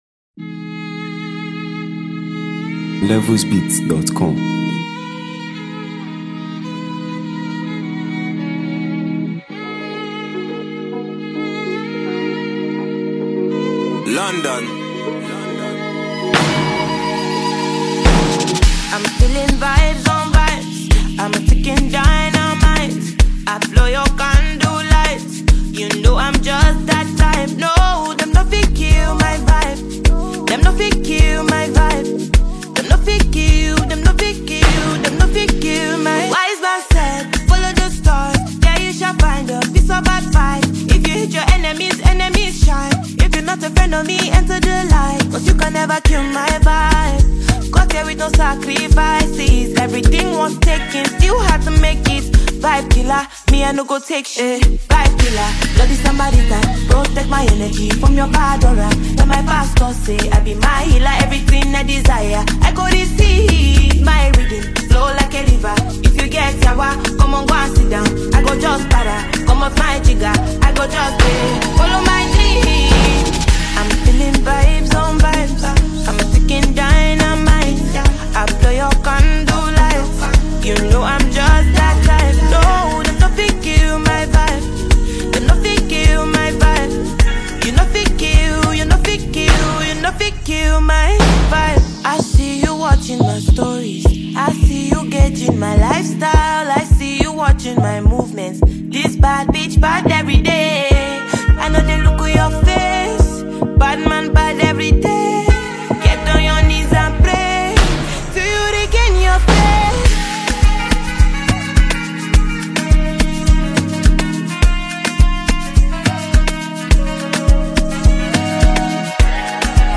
Afropop
Known for her captivating vocals